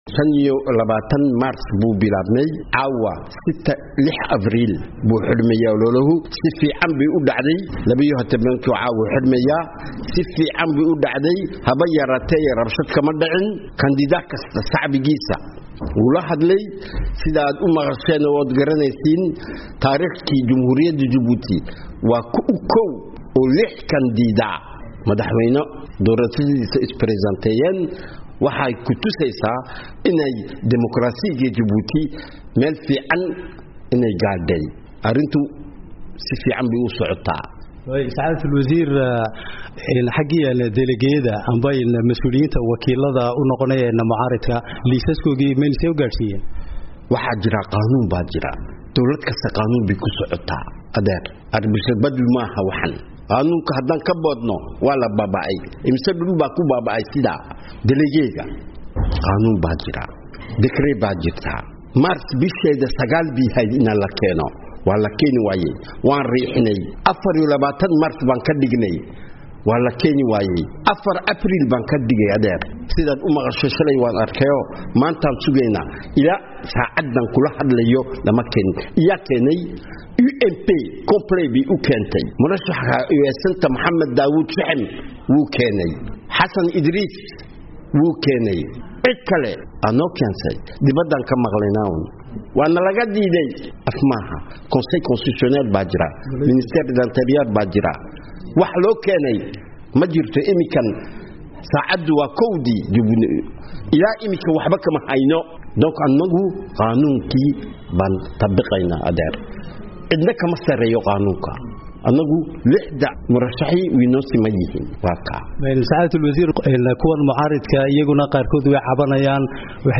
wareysi gaar ah